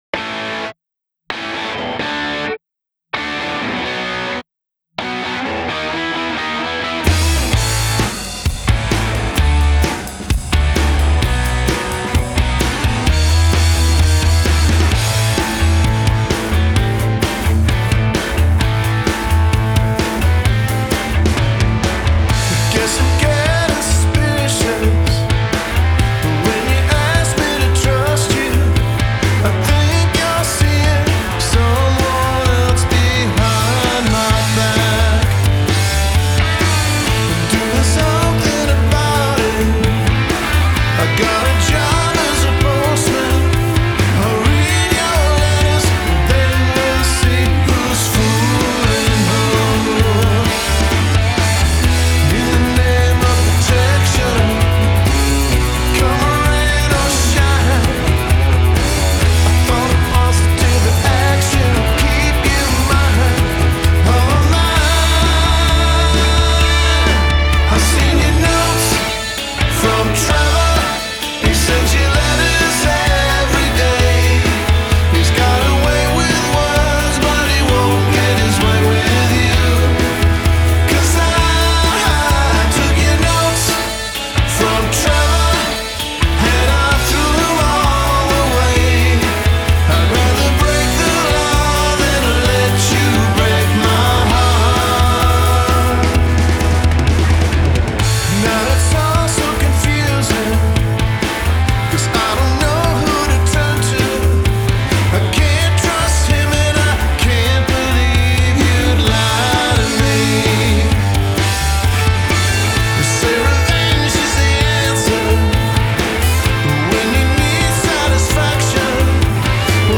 killer build up and knock out earwormy chorus